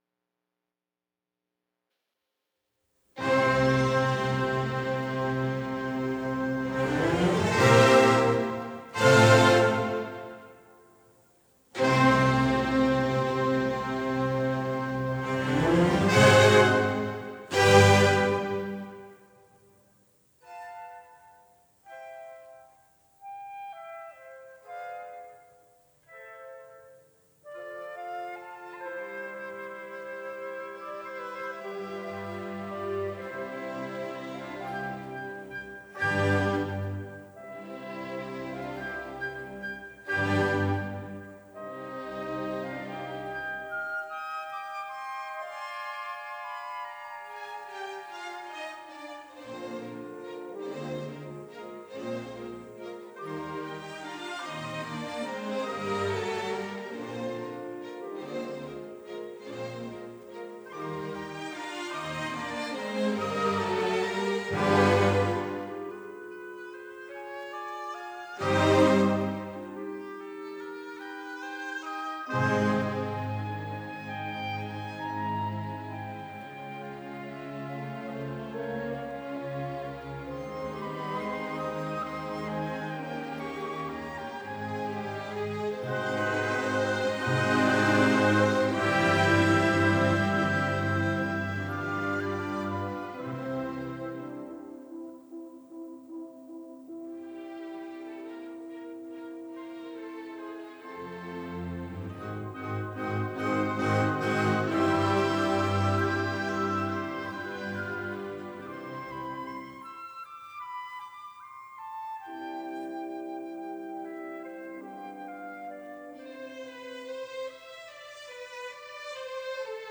Orchestra
Recorded At – Watford Town Hall July 1958